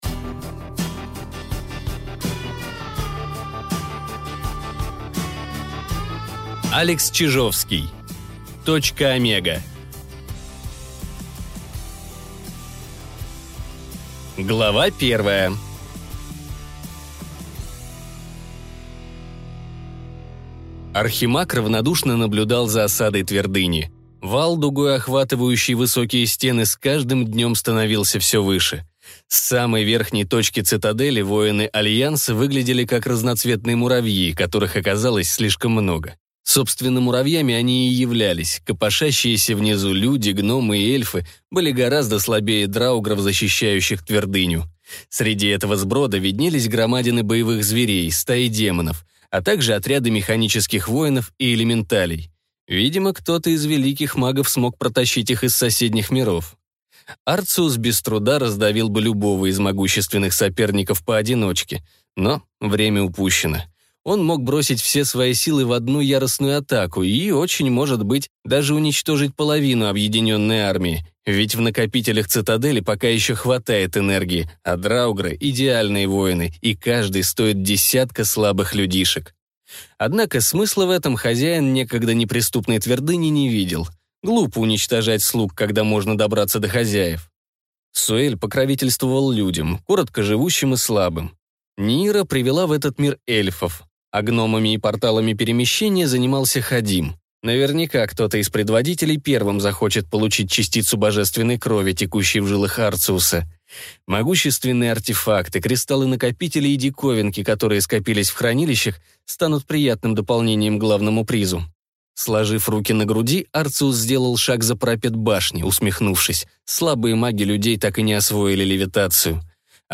Аудиокнига Точка Омега | Библиотека аудиокниг